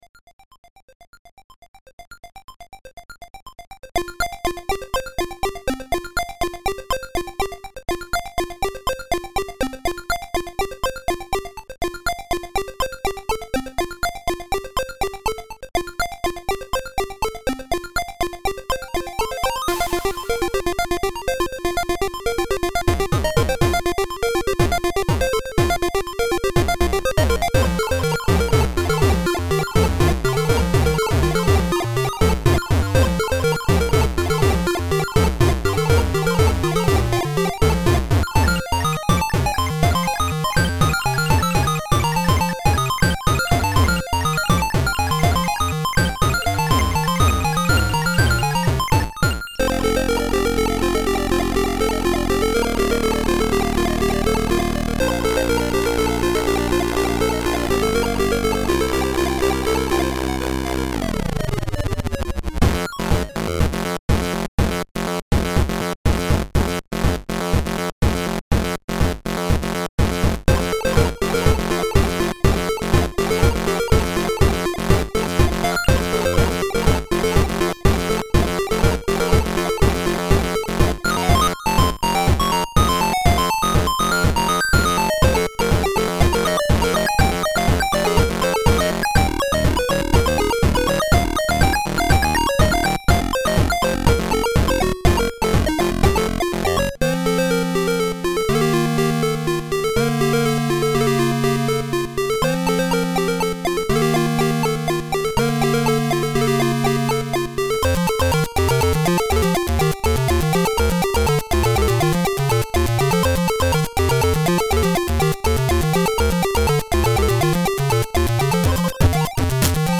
ZX Spectrum + AY
• Sound chip AY-3-8912 / YM2149